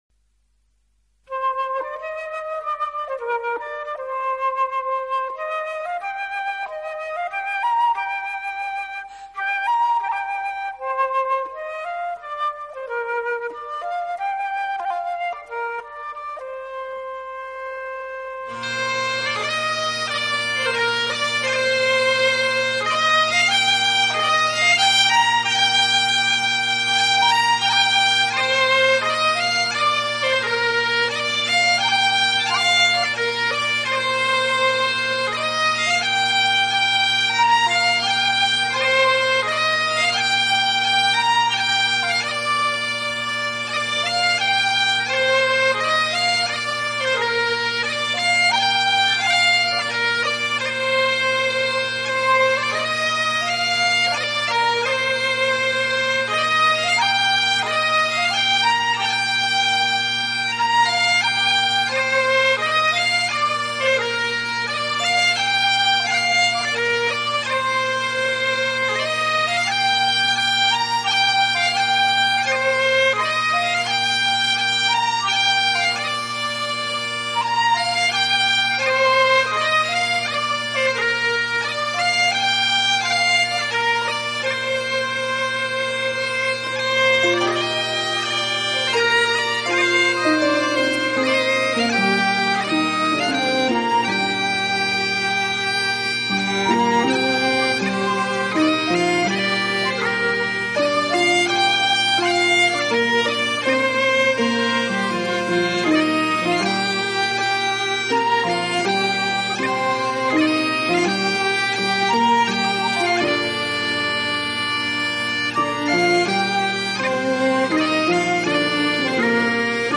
以只运用传统乐器著称
凄美的意境也值得凯尔特乐友 静心聆听